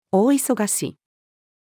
very-busy-person-or-thing-female.mp3